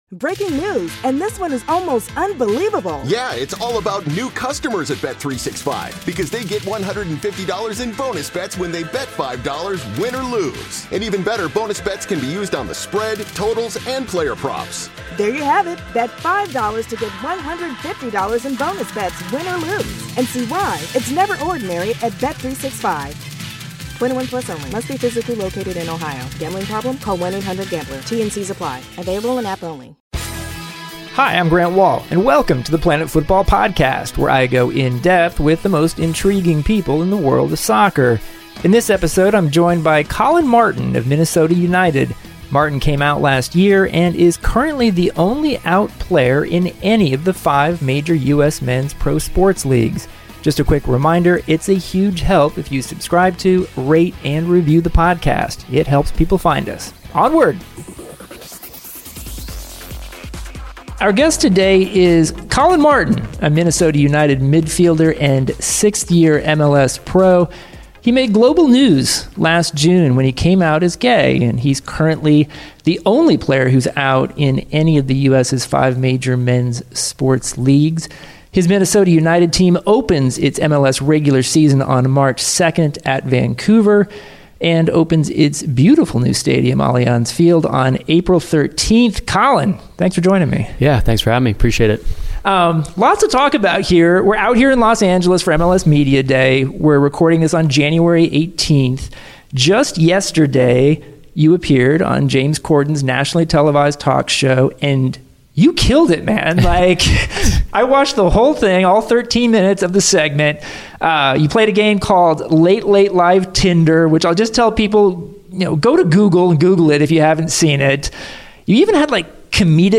Grant sits down with Minnesota United's Collin Martin, a creative midfielder who's entering his seventh MLS season. Last year Martin came out as gay on Pride Day and received an outpouring of support from people around the world. He and Grant have a wide-ranging conversation about a number of topics.